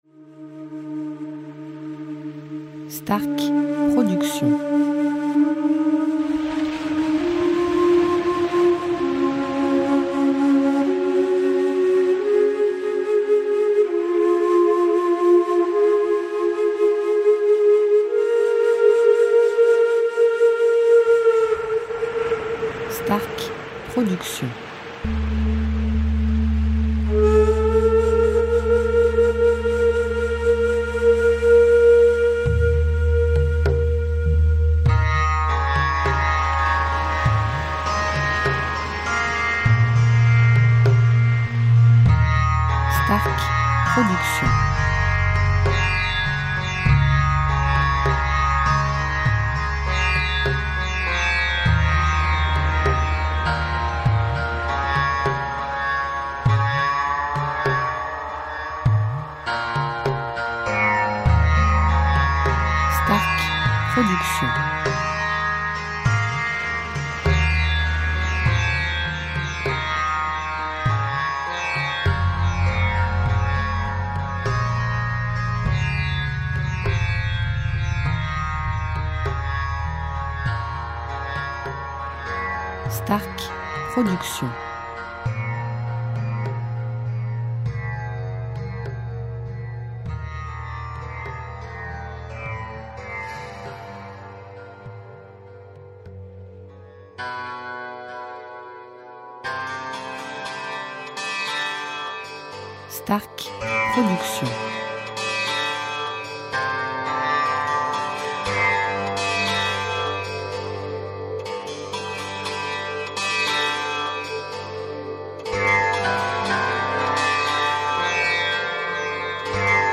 style Ayurvédique durée 1 heure